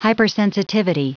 Prononciation du mot hypersensitivity en anglais (fichier audio)
Prononciation du mot : hypersensitivity